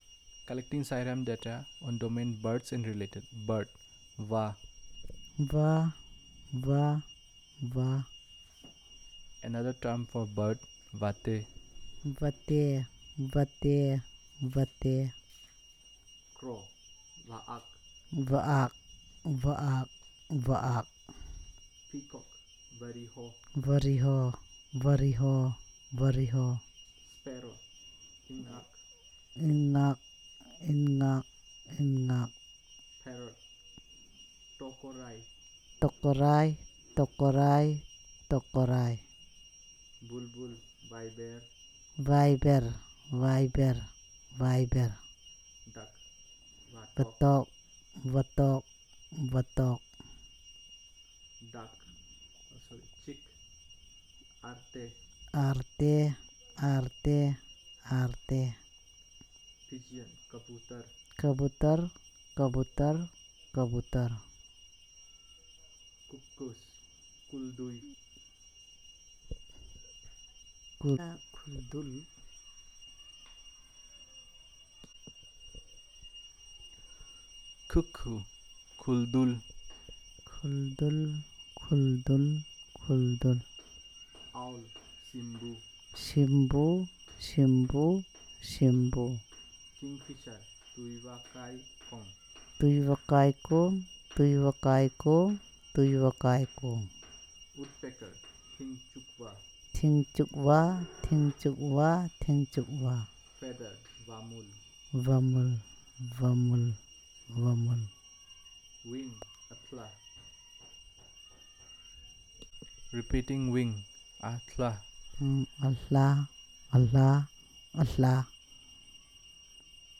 dc.type.discoursetypeElicitation